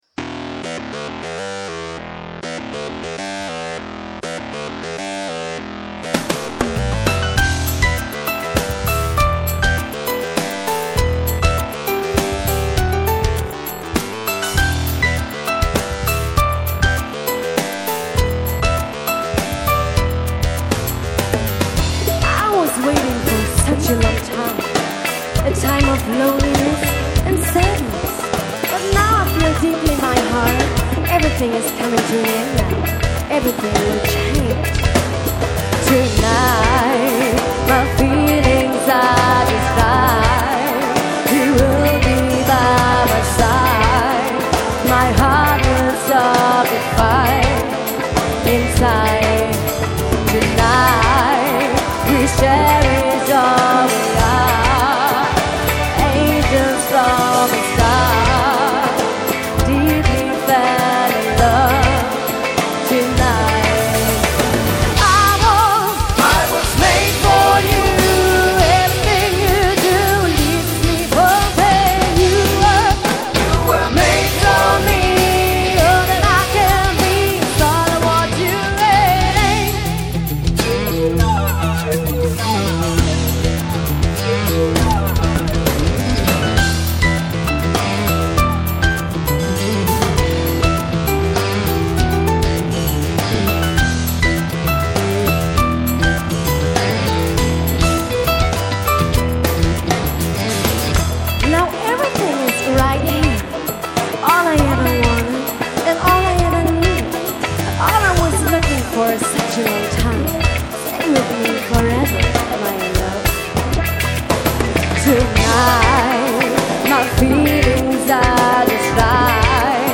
Gesang
Gitarre
Drums
Bass, Keys, Percussion
Pop Rock